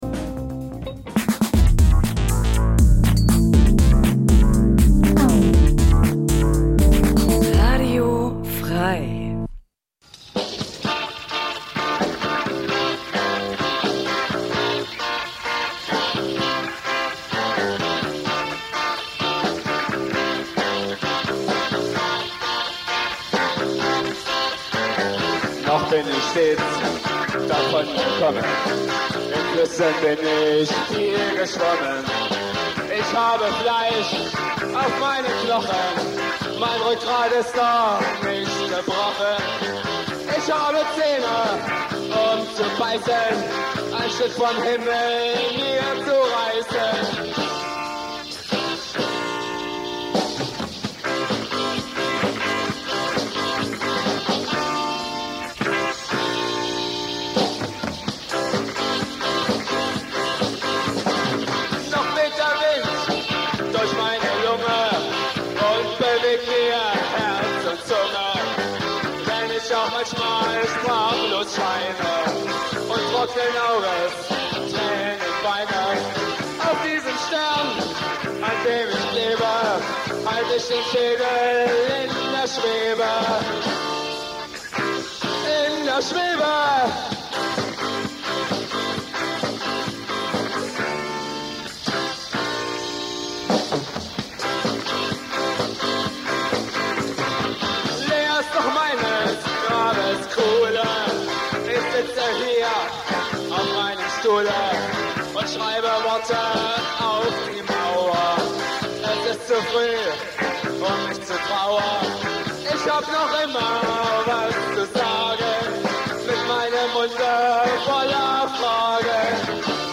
Gespr�ch